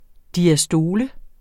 Udtale [ diaˈsdoːlə ]